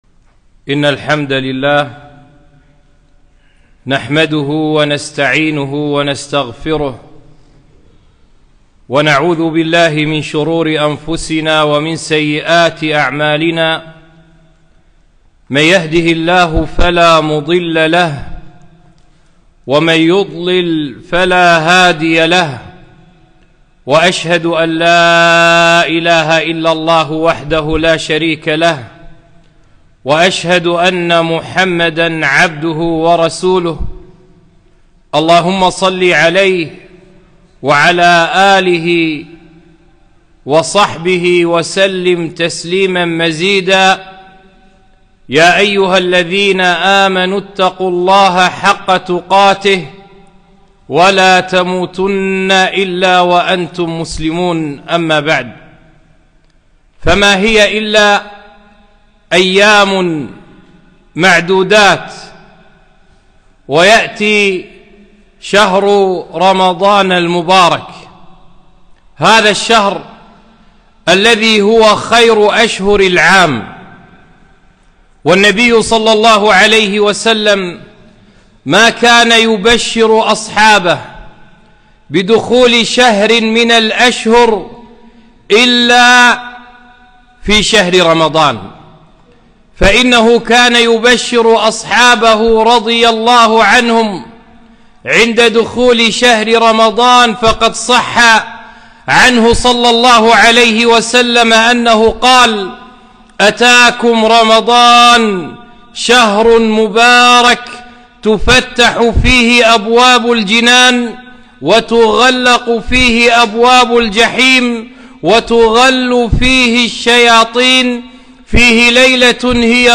خطبة - أتاكم رمضان